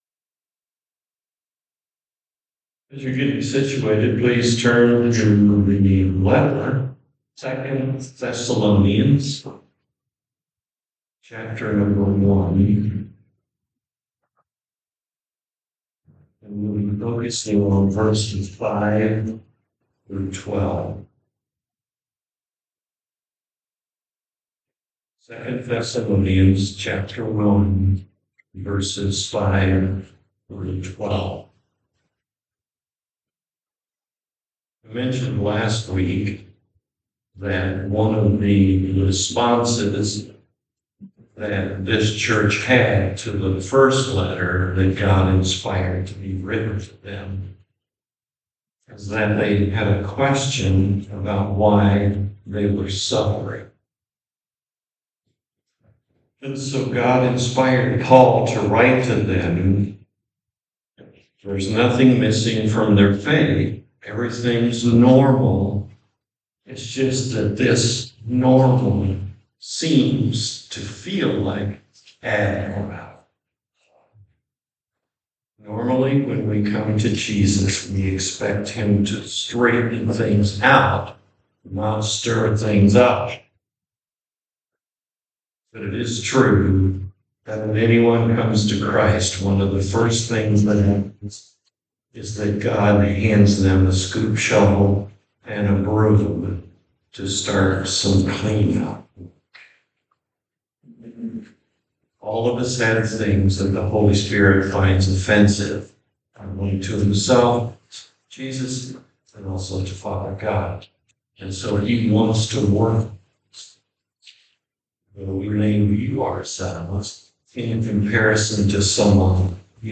2 Thessalonians Passage: 2 Thessalonians 1:5-12 Service Type: Morning Worship « Is There Something Missing Here?